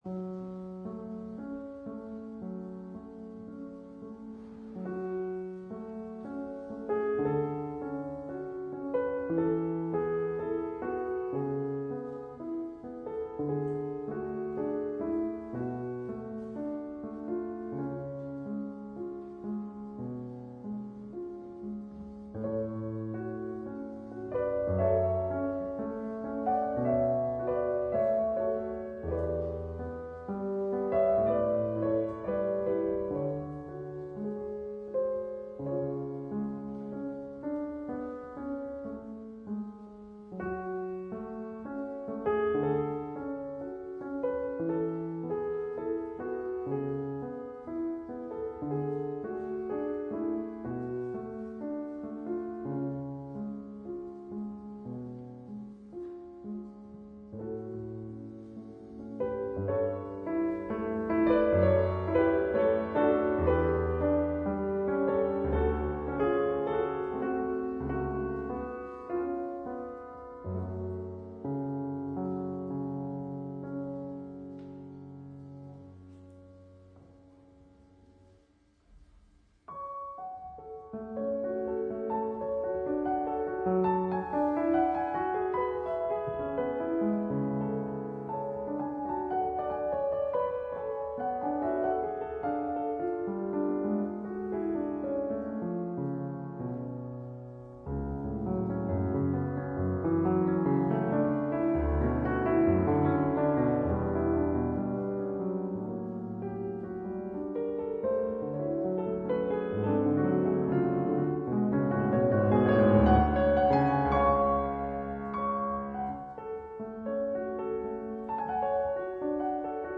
Variazioni e fantasia
PIANOFORTE